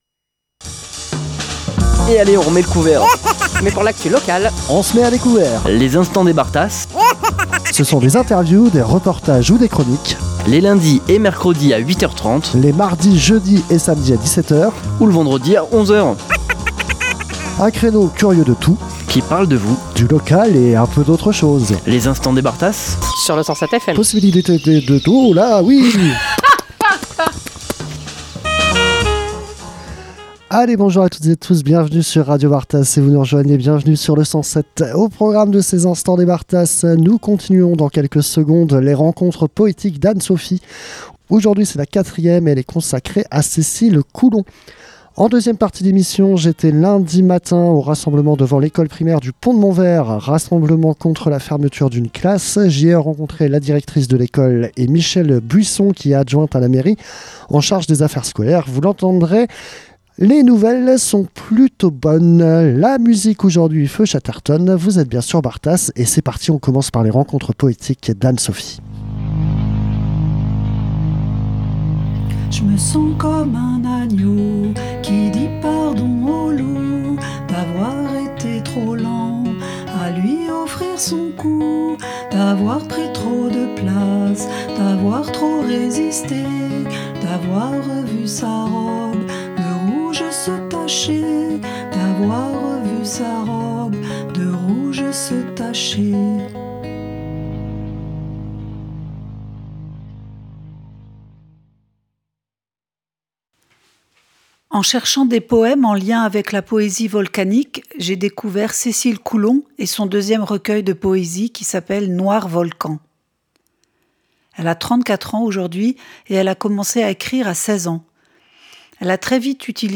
Le rassemblement contre la fermeture d'une classe au Pont de Montvert aujourd'hui.
S10_Mardi_Manif_PontdeMonvert.mp3